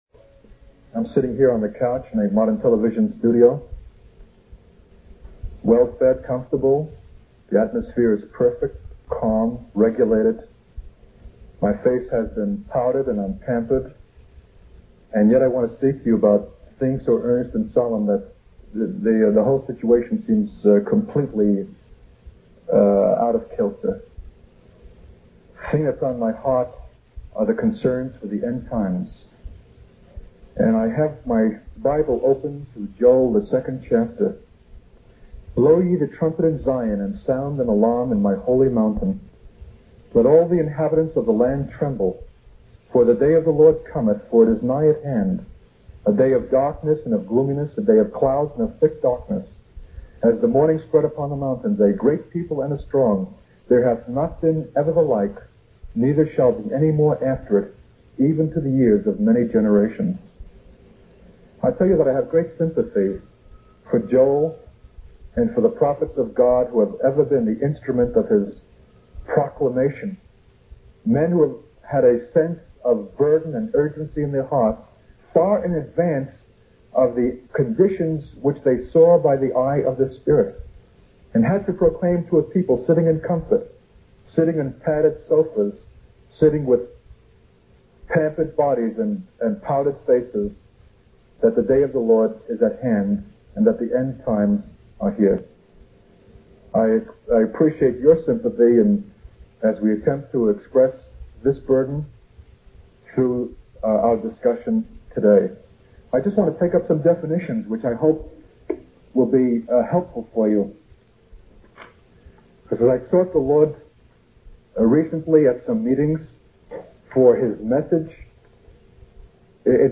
Two back-to-back messages (25 minutes each)